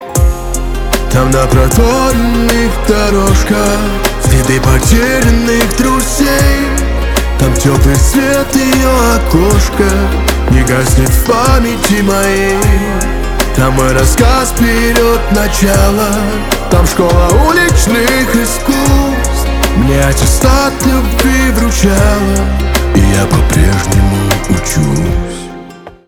поп
грустные